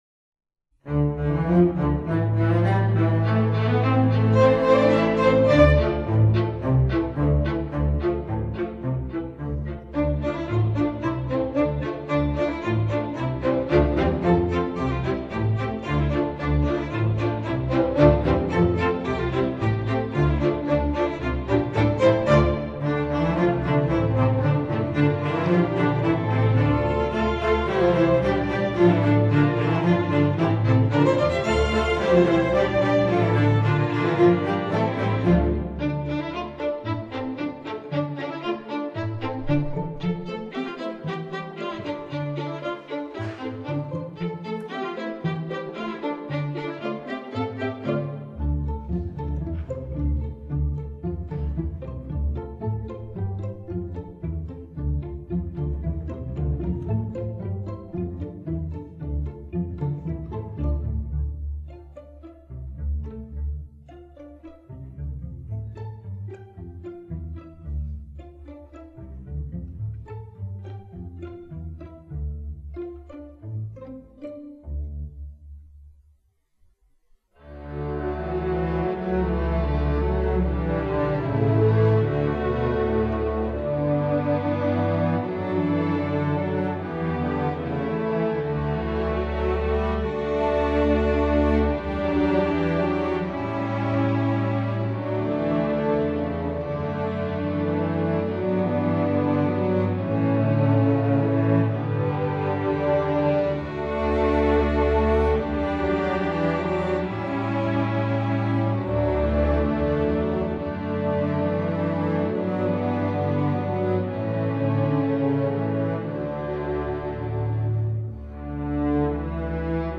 instructional, children